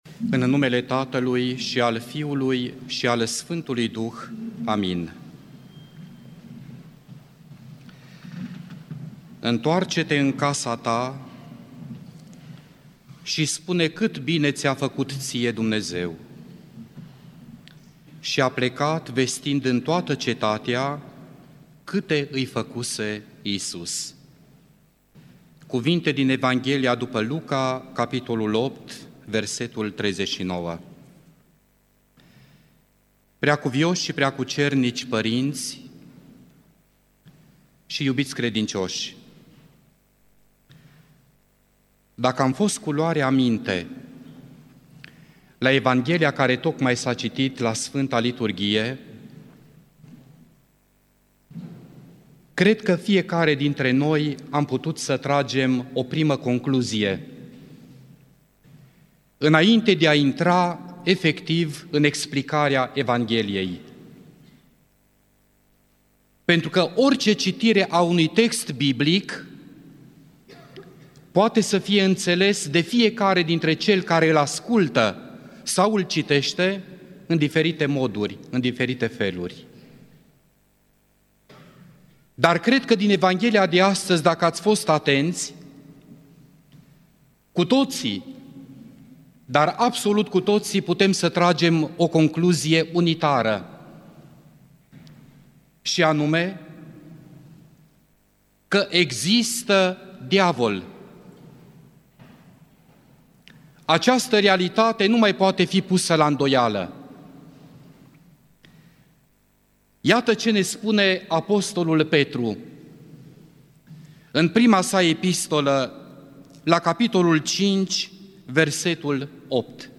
Predică la Duminica a 23-a după Rusalii (Vindecarea demonizatului din ținutul Gherghesenilor)
rostit la Catedrala Mitropolitană din Cluj-Napoca